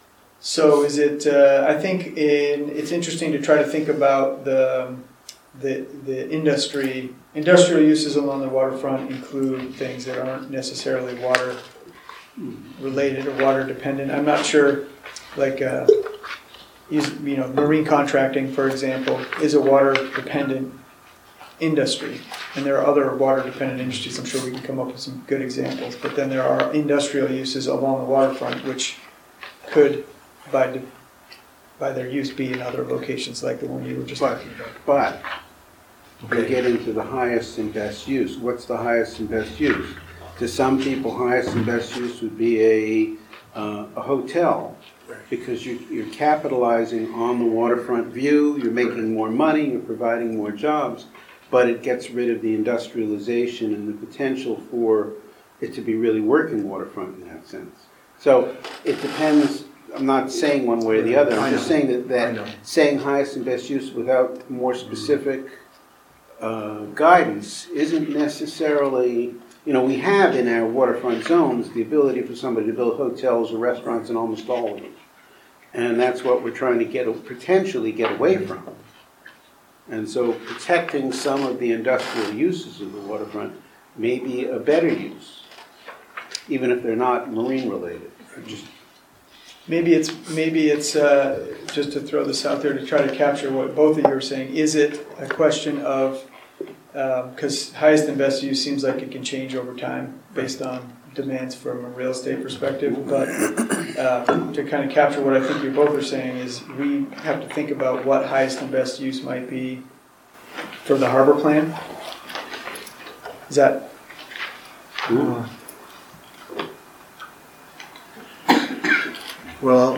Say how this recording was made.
The Rockland city council met 9/9/19.